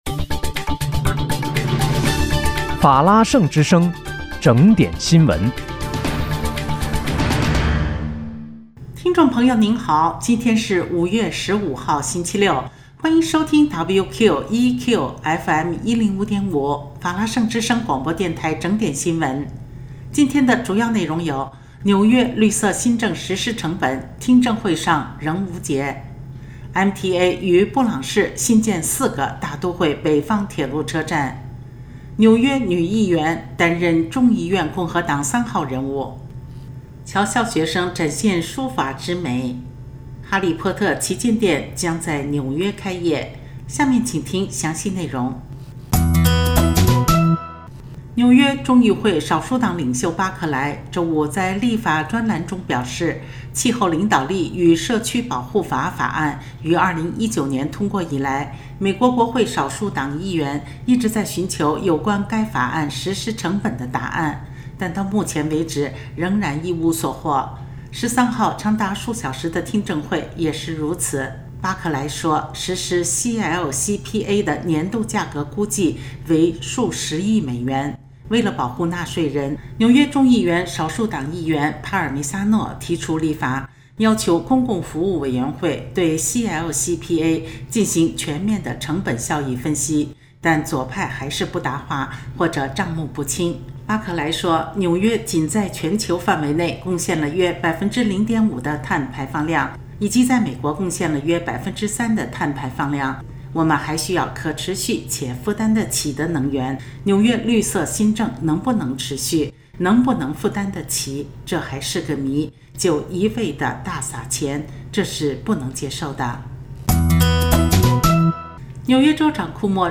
5月15日（星期六）纽约整点新闻
听众朋友您好！今天是5月15号，星期六，欢迎收听WQEQ105.5法拉盛之声广播电台整点新闻。